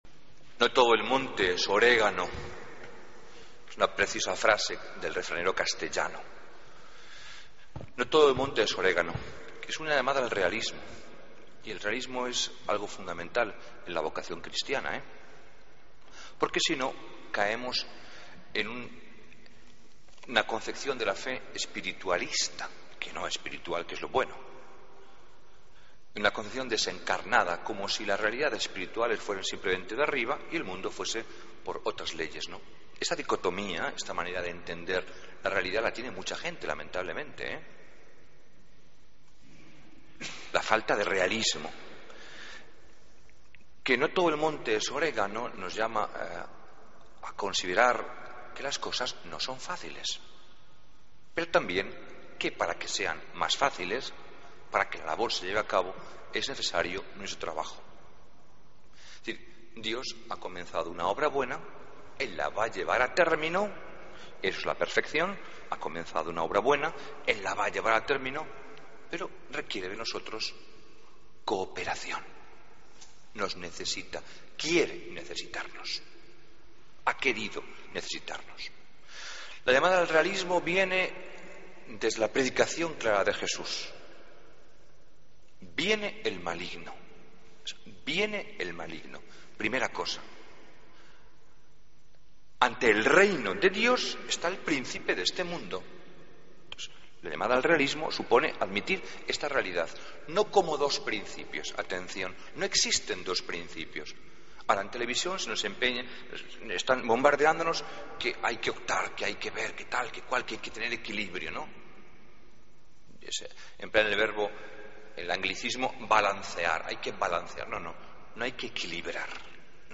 Homilía del Domingo 13 de Julio de 2014